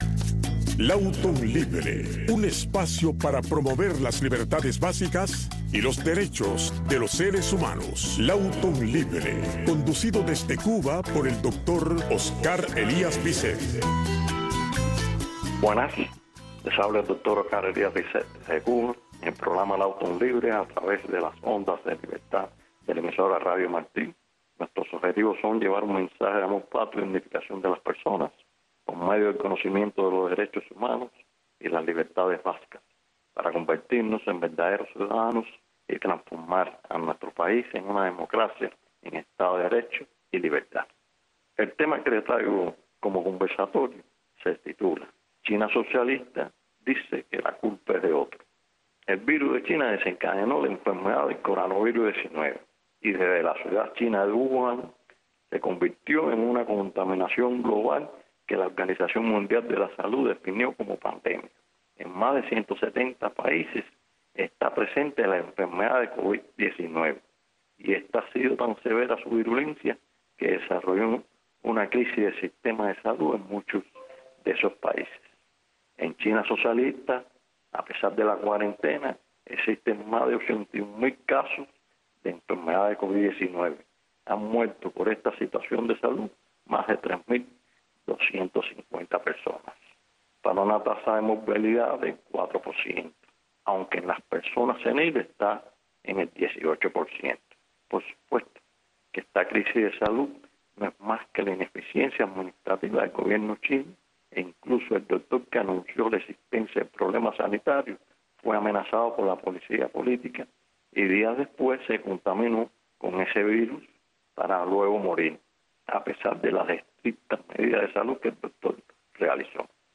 Lawton Libre es el programa conducido por el Doctor Oscar Elías Biscet que te habla de los derechos humanos, de las libertades básicas y de cómo lograr la libertad, tu libertad, porque si aprendes a ser libre todos los seremos Todos los sábados a las 7 am y también los sábados y domingos a las 11 de la noche en Radio Martí.